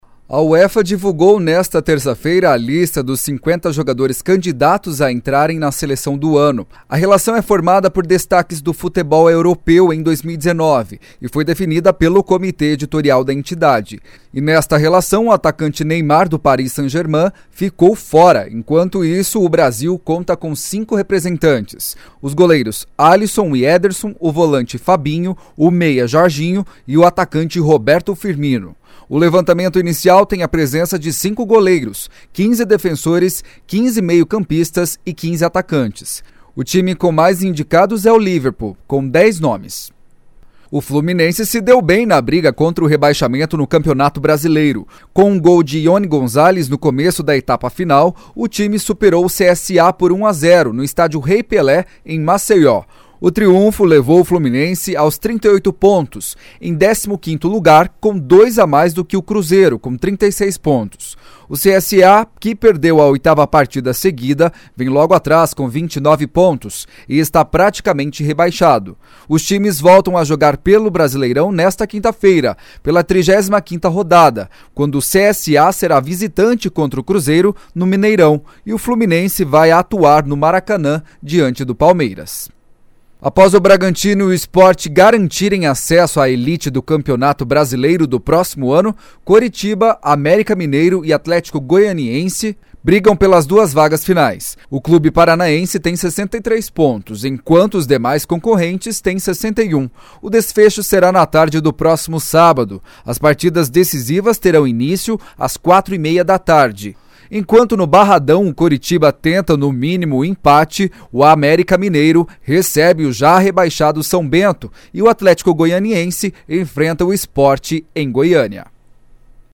Giro Esportivo SEM TRILHA